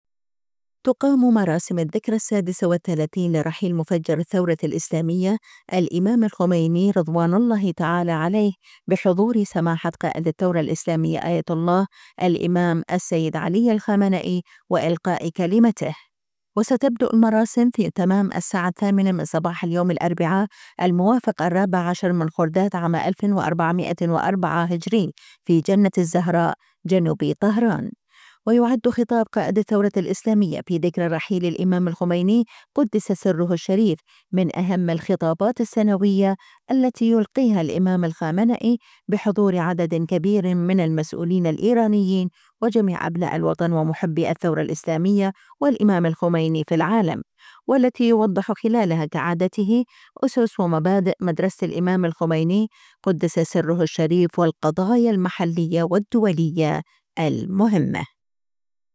قائد الثورة الإسلامية يلقي كلمة اليوم في الذكرى السنوية لرحيل الامام الخميني (رض)
تقام مراسم الذكرى السادسة والثلاثين لرحيل مفجر الثورة الإسلامية الإمام الخميني (رض) بحضور سماحة قائد الثورة الإسلامية آية الامام السيد علي الخامنئي، وإلقاء كلمته.
وستبدأ المراسم في تمام الساعة الثامنة من صباح اليوم الأربعاء، الموافق 14 خرداد 1404 هـ، في جنة الزهراء جنوبي طهران.